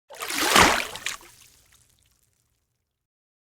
Paddle Water Singel Shot
Nature
yt_4IxCl9LpNRA_paddle_water_singel_shot.mp3